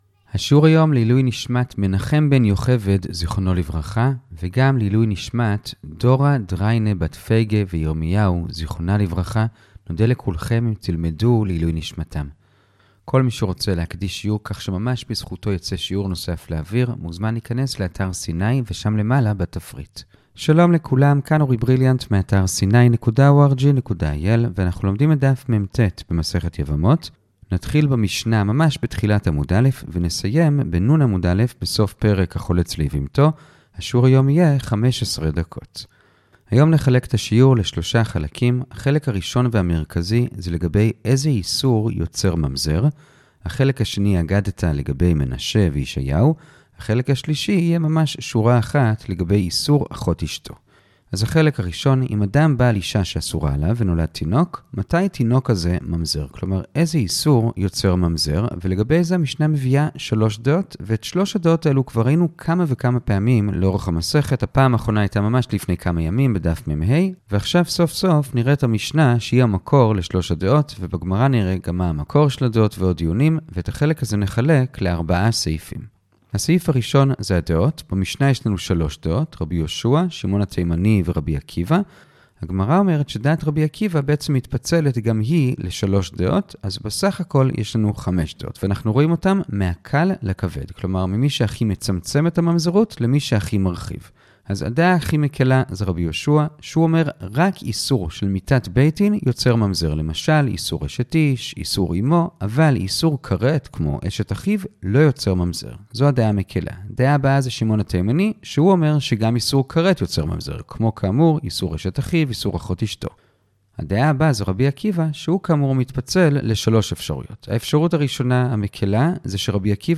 הדף היומי - יבמות מט - הדף היומי ב15 דקות - שיעורי דף יומי קצרים בגמרא